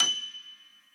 b_piano1_v100l8-11o8fp.ogg